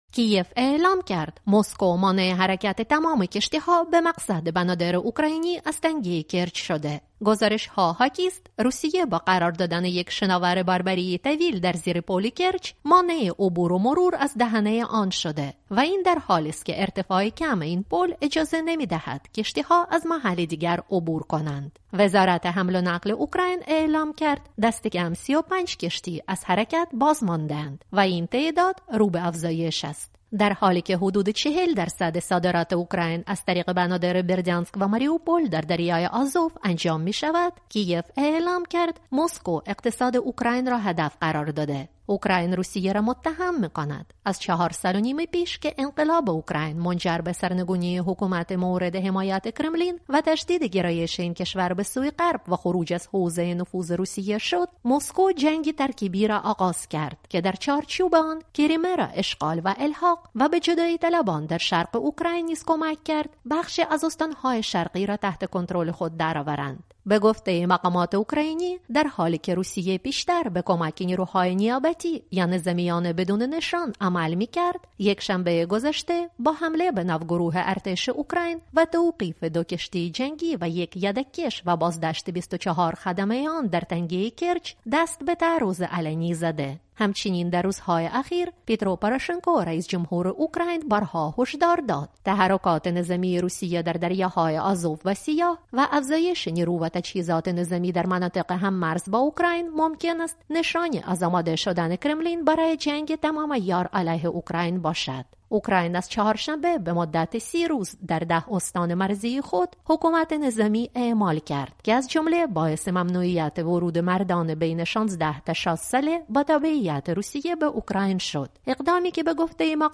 گزارش
از کی‌یف درباره آخرین تحولات مربوط به تنش بین روسیه و اوکراین